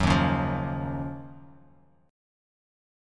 D#m9 chord